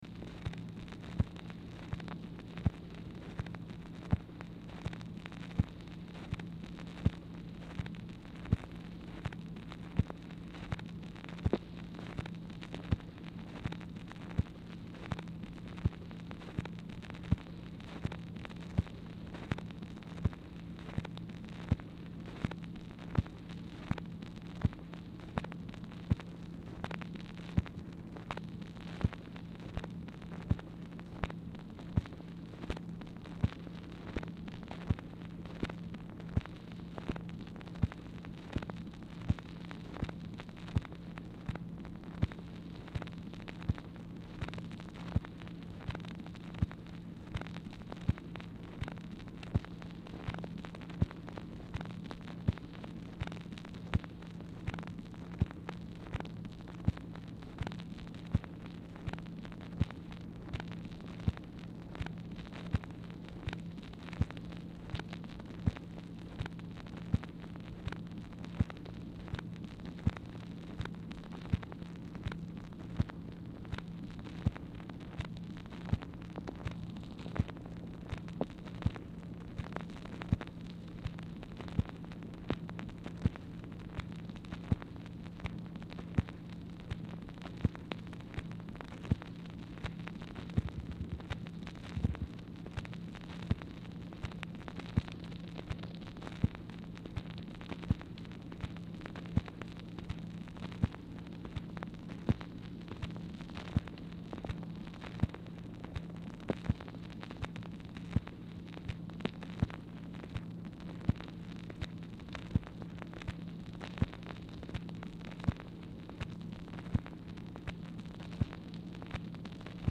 Telephone conversation # 3003, sound recording, MACHINE NOISE, 4/10/1964, time unknown
MACHINE NOISE
Telephone conversation
Dictation belt